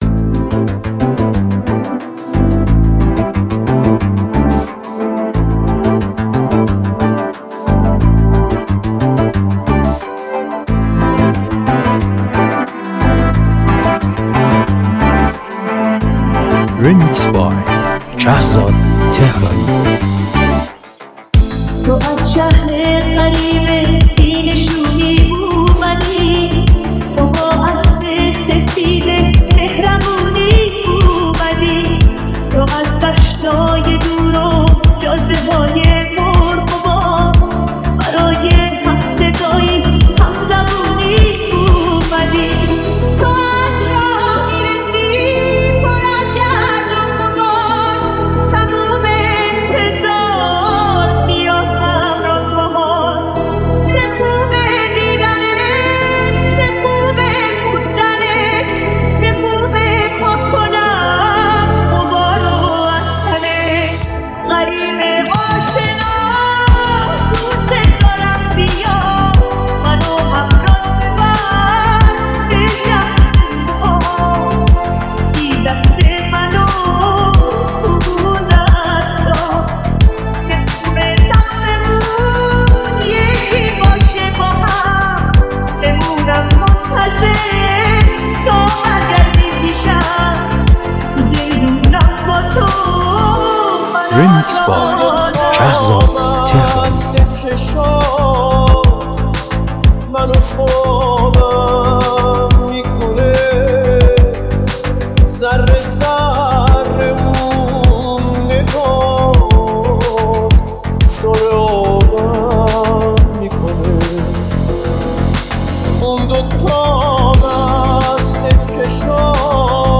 انرژی خالص برای دور دور شبانه